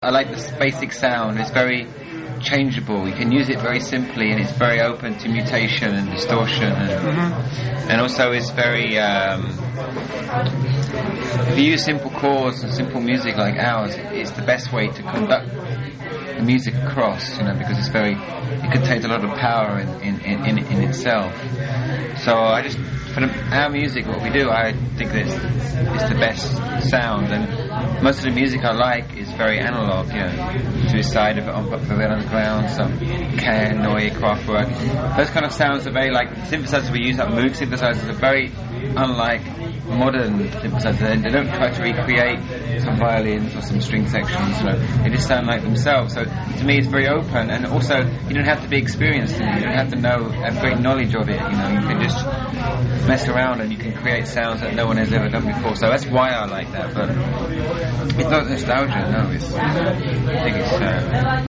Intervju: STEREOLAB